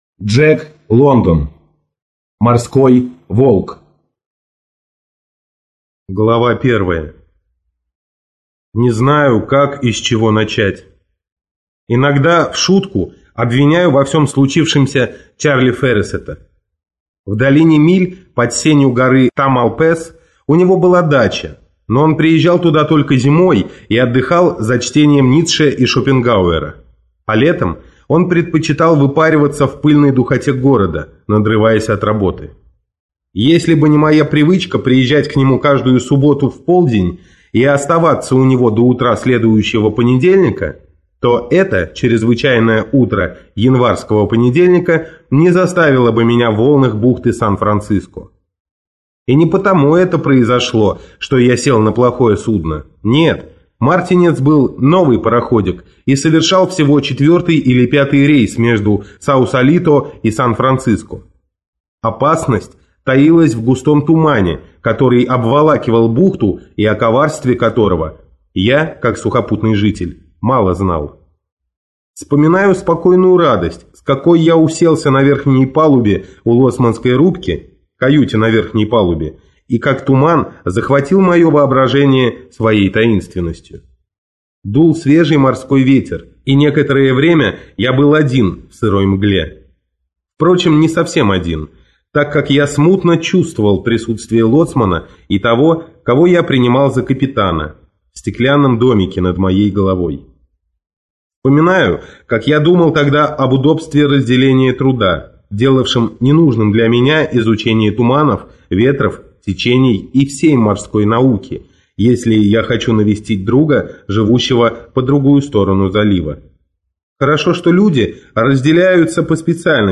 Аудиокнига Морской волк - купить, скачать и слушать онлайн | КнигоПоиск